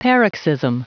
Prononciation du mot paroxysm en anglais (fichier audio)
Prononciation du mot : paroxysm